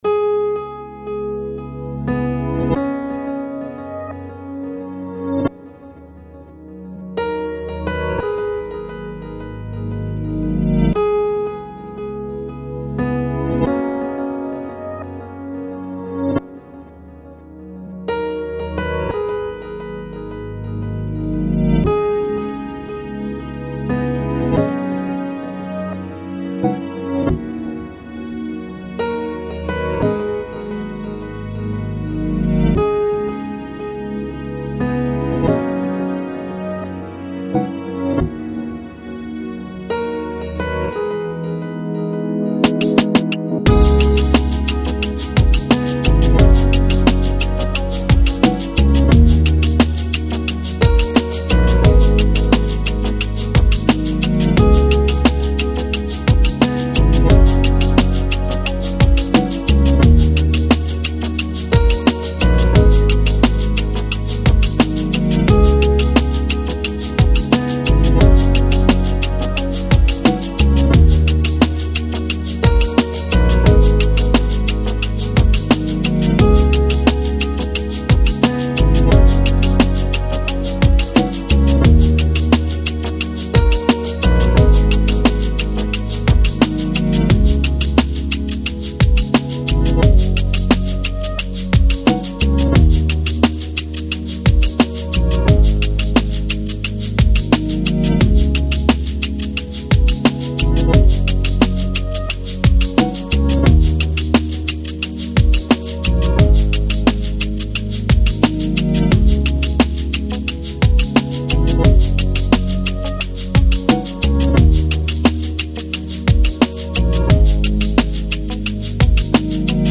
Lounge, Chill Out, Downtempo Год издания